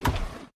DrawerOpen.mp3